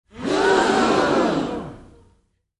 small_crowd_gasping